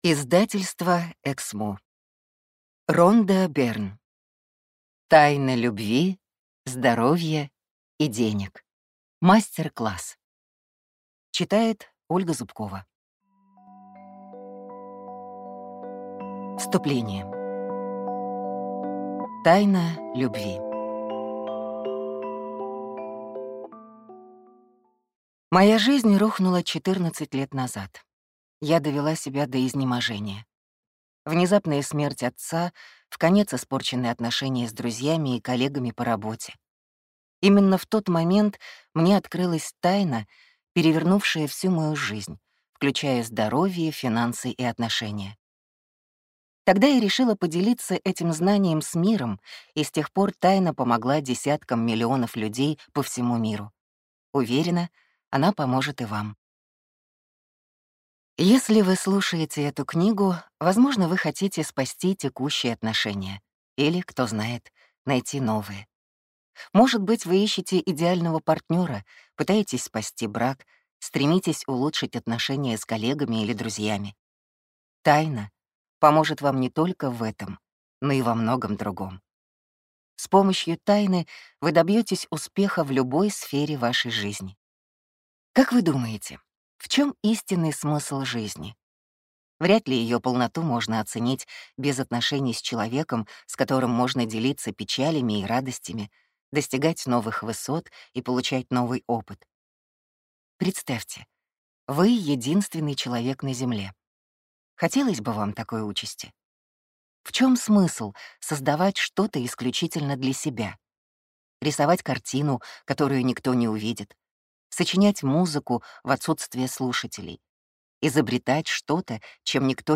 Аудиокнига Тайна любви, здоровья и денег. Мастер-класс | Библиотека аудиокниг